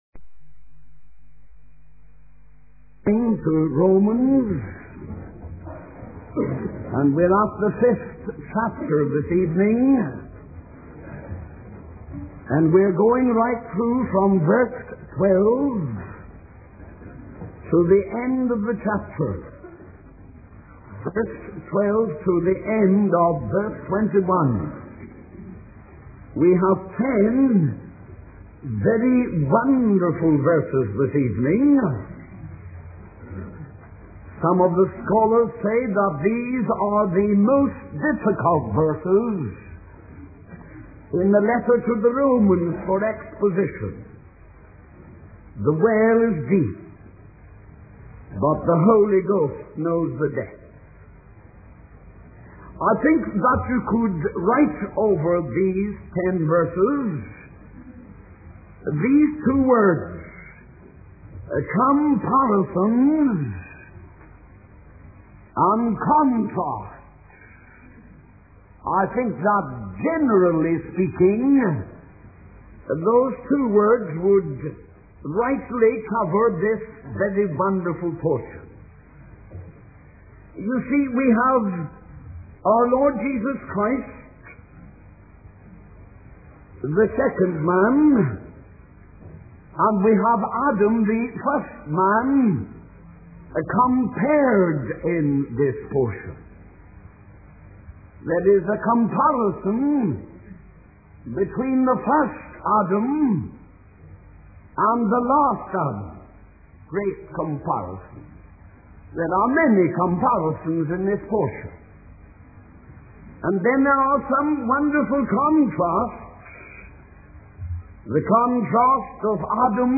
In this sermon, the preacher emphasizes the concept of salvation through Jesus Christ.